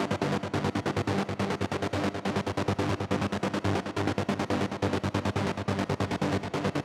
VTS1 Space Of Time Kit Bassline